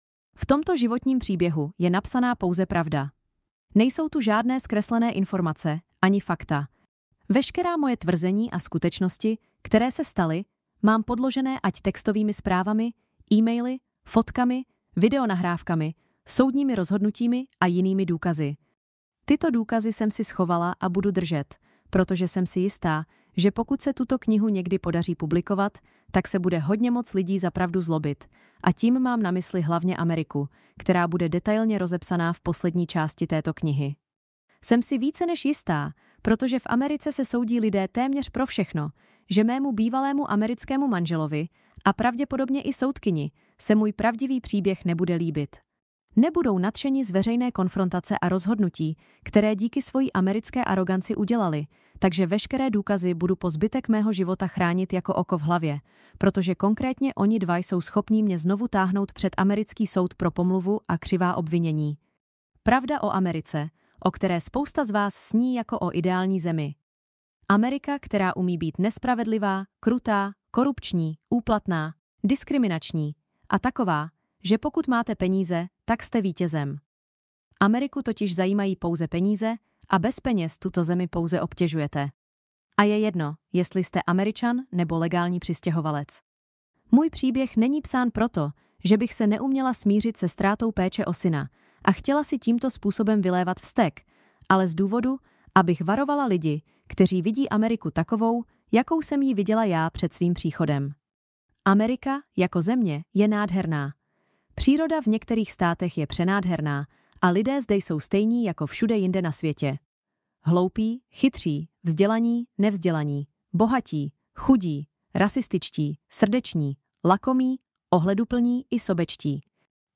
Životní příběh striptérky – audiobook – 249 Kč
Ukázka z úvodu knihy
Hlas možná generovaný, ale sympatický a dobře se poslouchá.
Demo_CZ_audiokniha.mp3